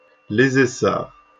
来自 Lingua Libre 项目的发音音频文件。 语言 InfoField 法语 拼写 InfoField Les Essards 日期 2020年5月4日 来源 自己的作品